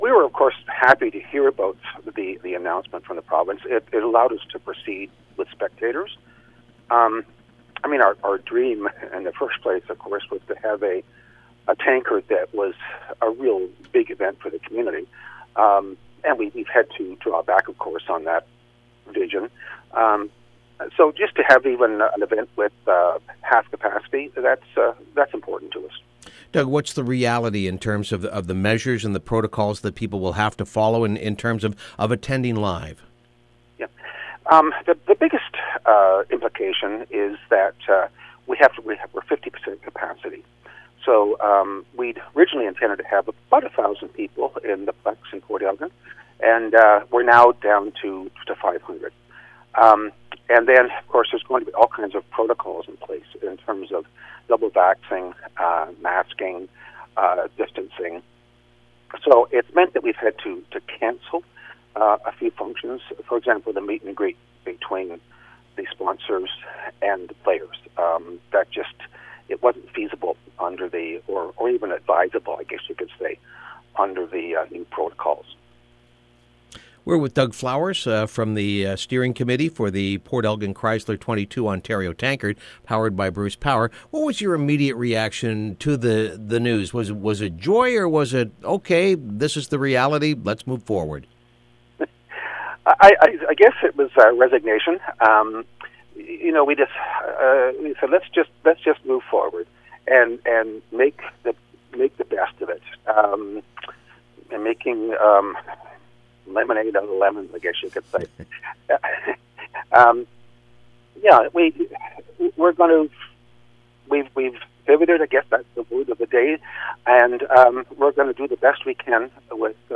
A Dozen To Compete For Tankard. Interview